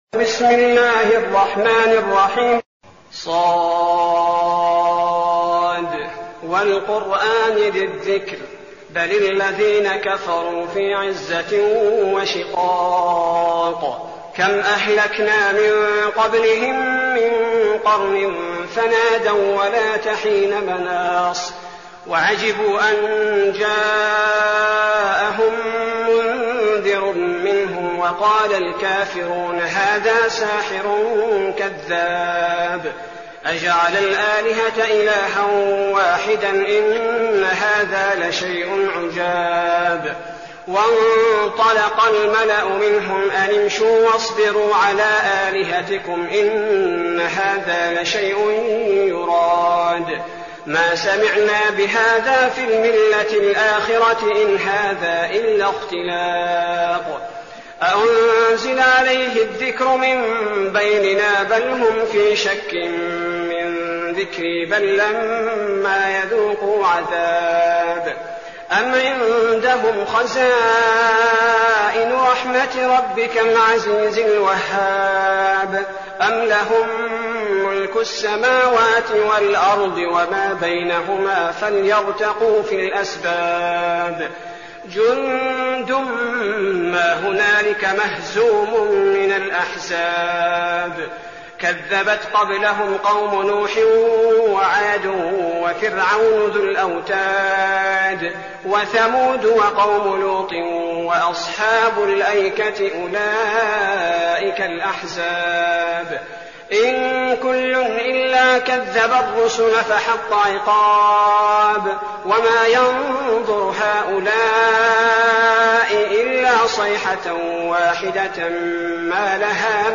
المكان: المسجد النبوي الشيخ: فضيلة الشيخ عبدالباري الثبيتي فضيلة الشيخ عبدالباري الثبيتي ص